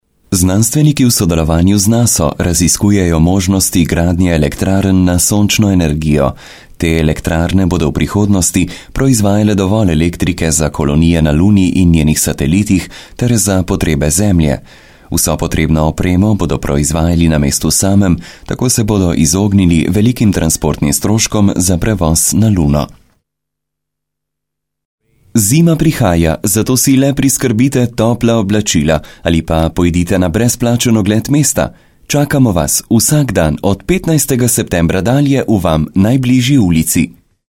Sprecher slowenisch für TV / Rundfunk / Industrie / Werbung. Muttersprachler.
Sprechprobe: Werbung (Muttersprache):
Professionell voice over artist from Slowenia.